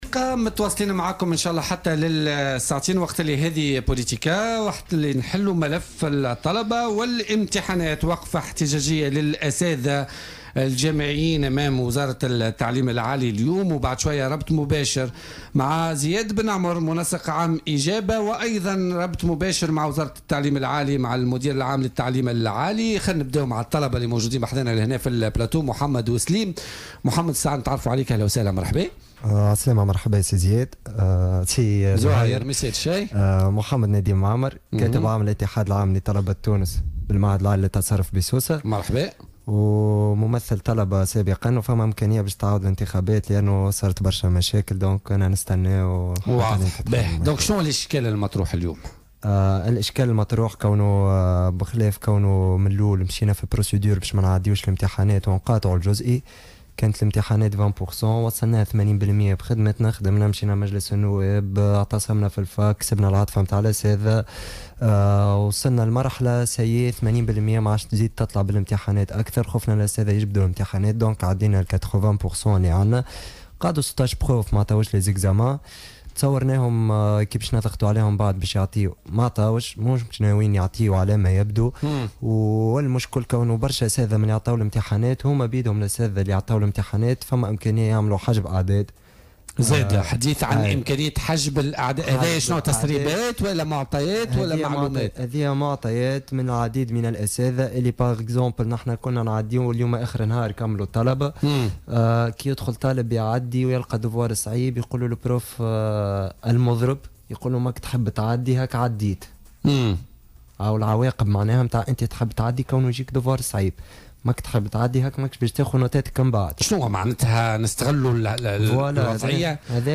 في تدخل هاتفي خلال برنامج بوليتكا